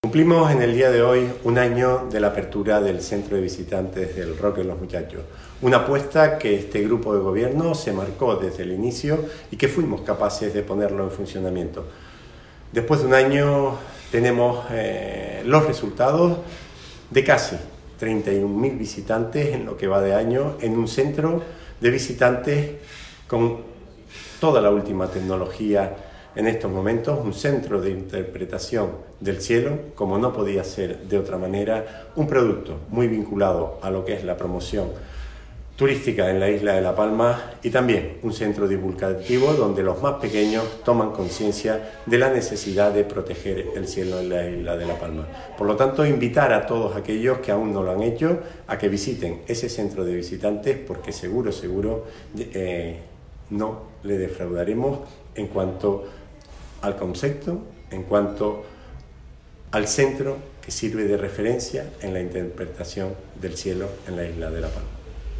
Declaraciones audio Raúl Camacho CVRM.mp3